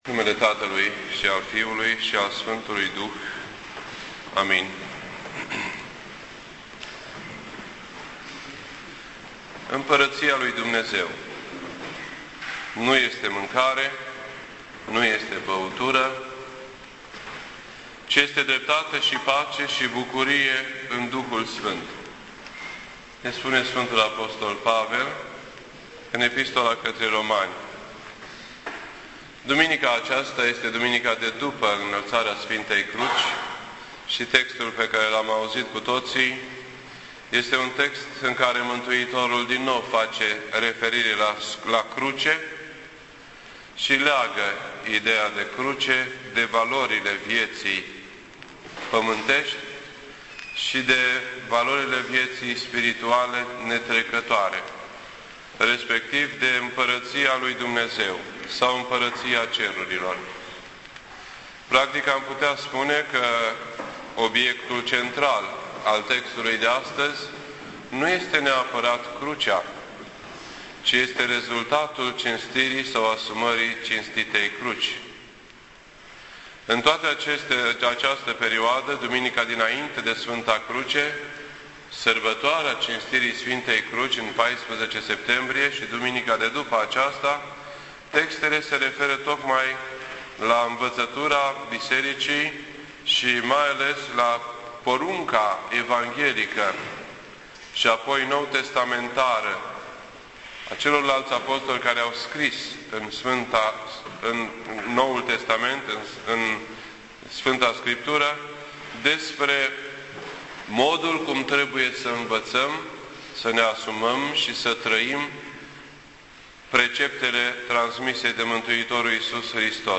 This entry was posted on Sunday, September 19th, 2010 at 9:12 PM and is filed under Predici ortodoxe in format audio.